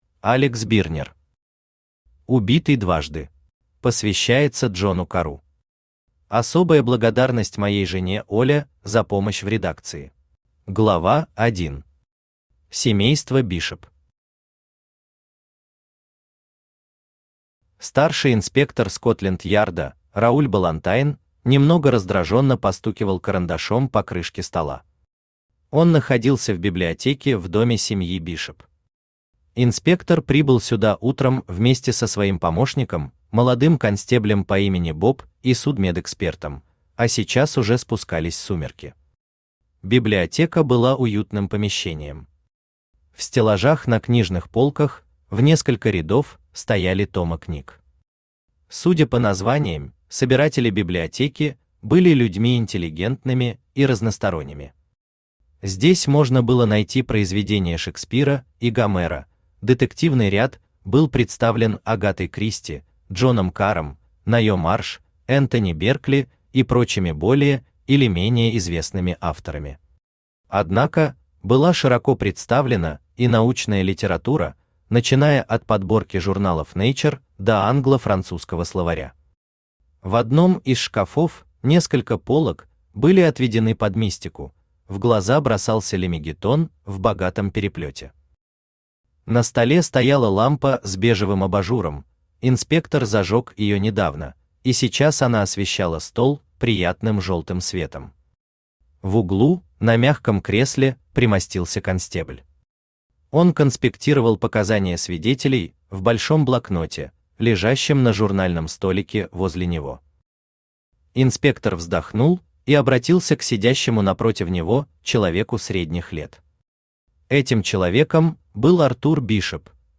Аудиокнига Убитый дважды | Библиотека аудиокниг
Aудиокнига Убитый дважды Автор Алекс Бирнер Читает аудиокнигу Авточтец ЛитРес.